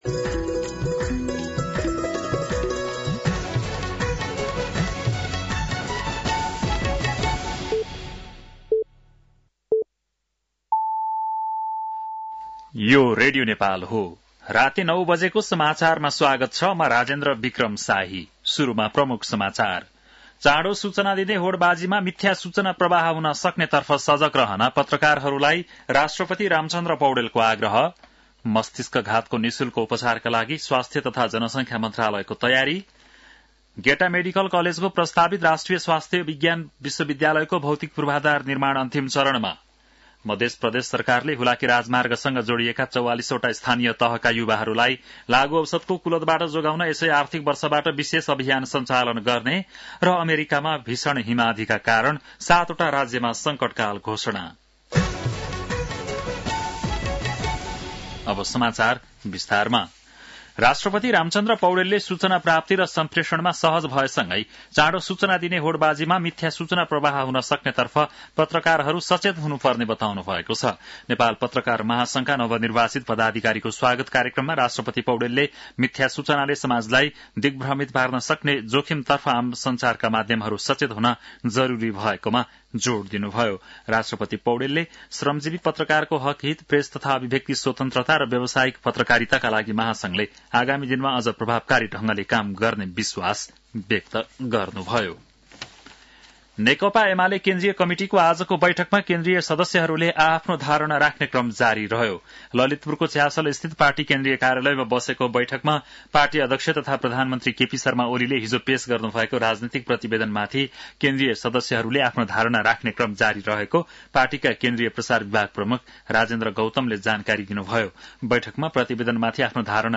An online outlet of Nepal's national radio broadcaster
बेलुकी ९ बजेको नेपाली समाचार : २३ पुष , २०८१